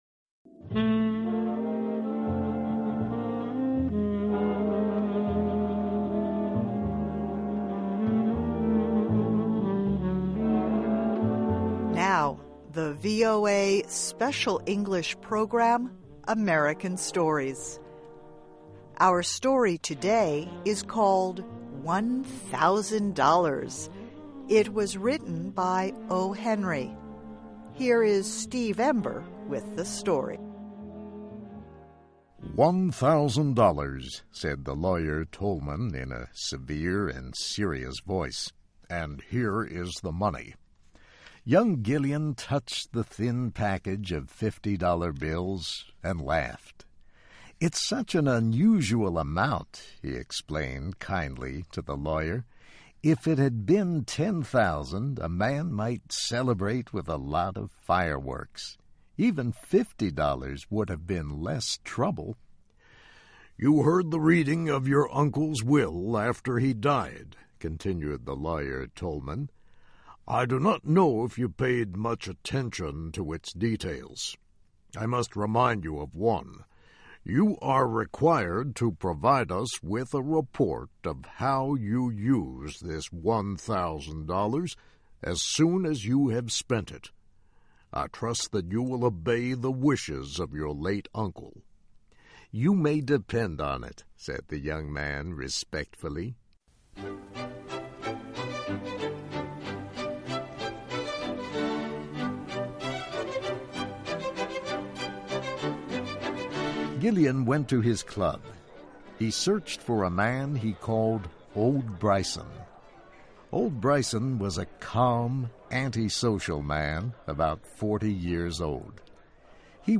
Learn English as you read and listen to a weekly show with short stories by famous American authors. Adaptations are written at the intermediate and upper-beginner level and are read one-third slower than regular VOA English.